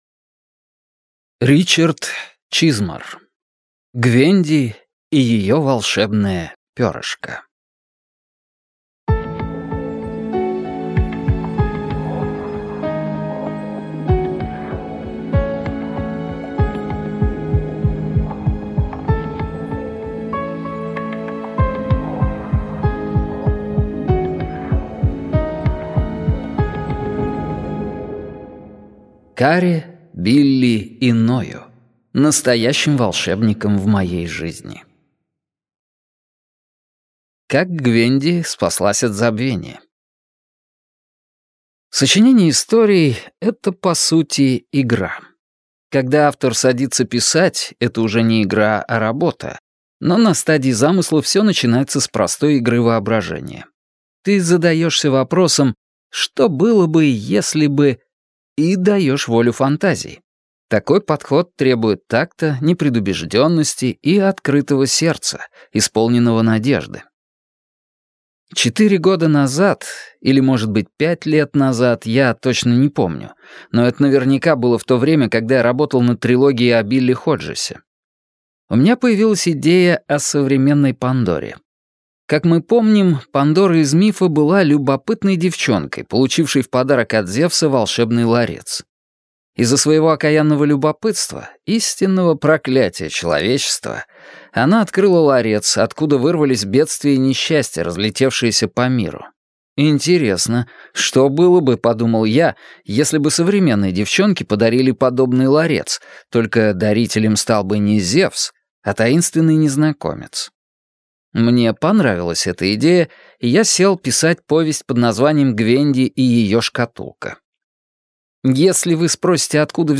Аудиокнига Гвенди и ее волшебное перышко | Библиотека аудиокниг